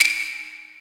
normal-hitwhistle.ogg